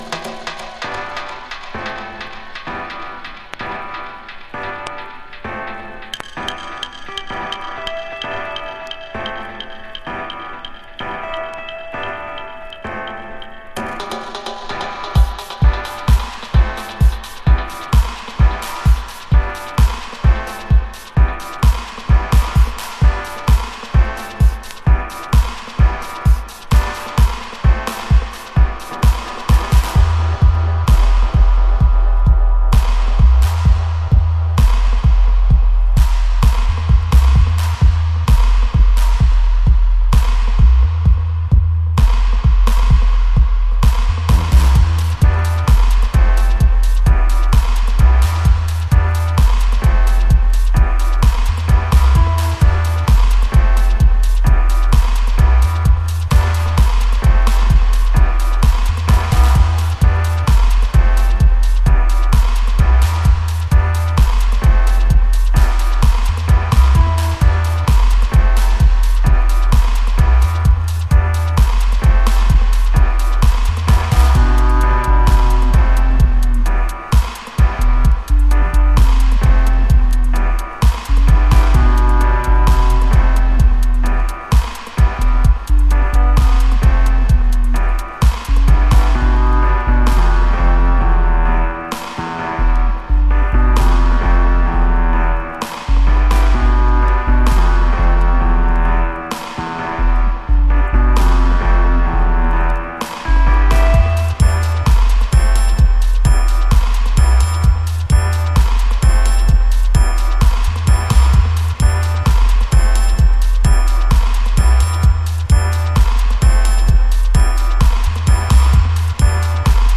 Ultra sub-n-dub heavy with melodica